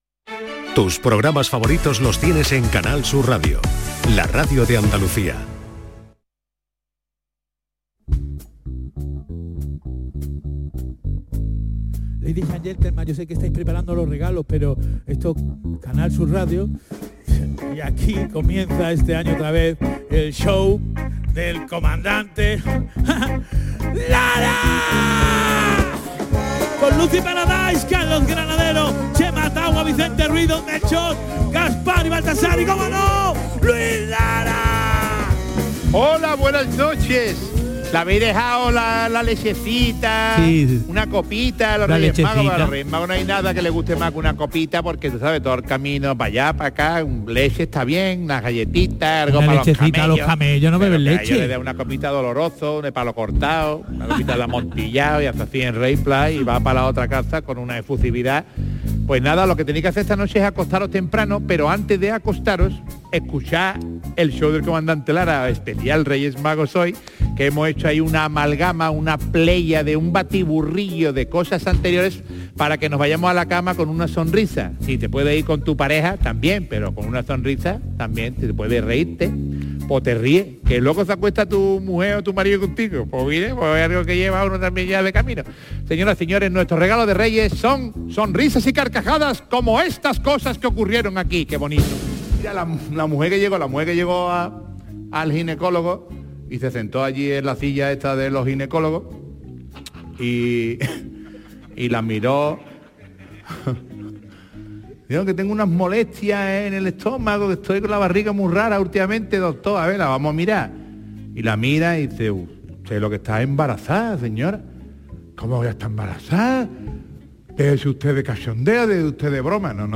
súbete a un recorrido fantástico por la risa, el humor más ingenioso y las entrevistas más originales. Canal Sur Radio | Domingos, justo después de la medianoche.